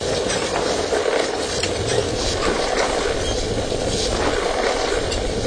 wheel_wood_01_22KHz.wav